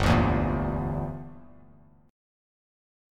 Gm6 Chord
Listen to Gm6 strummed